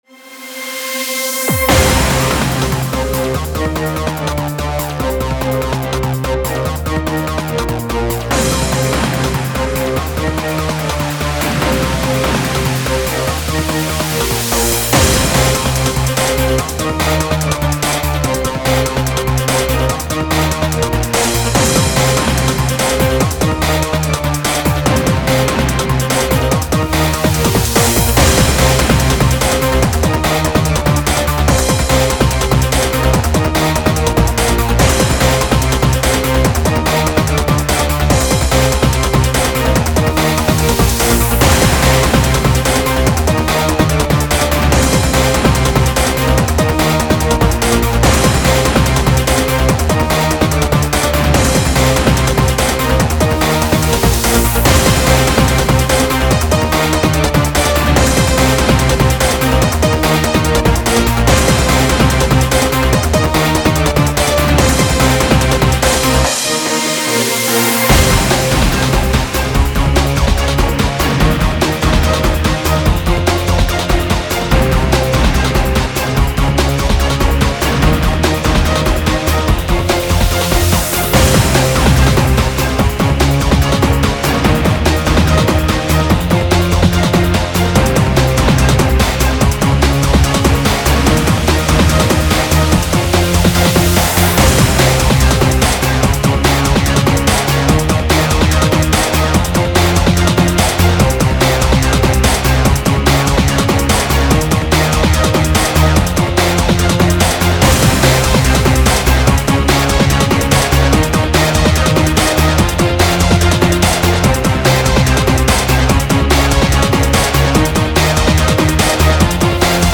industrial breakbeat combined with orchestra, heavily inspired by old waterflame's songs as well as race music from need for speed ...
145 bpm
Music / Industrial
breakbeat big_beat orchestral action orchestra industrial music song oc original fl_studio rave oldschool old_school experimental